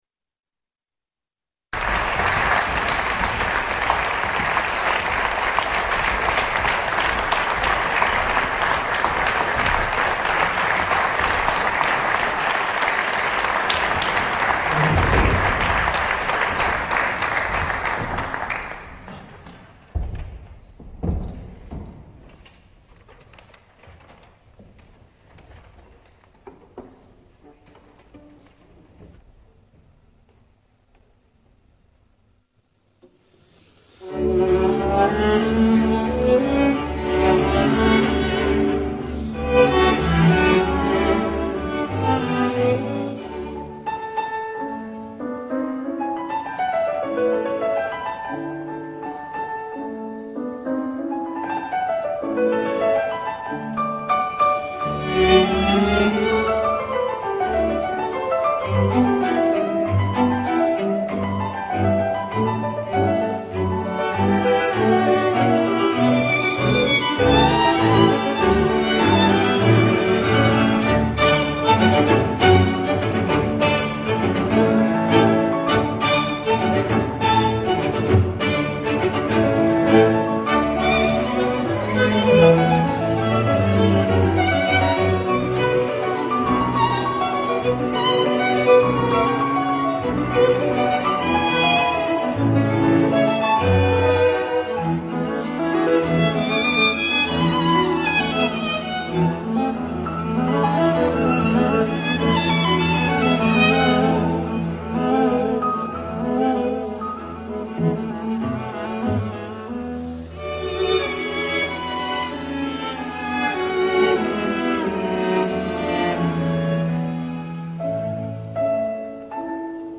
Group: Chamber
Members: String sextet, Piano sextet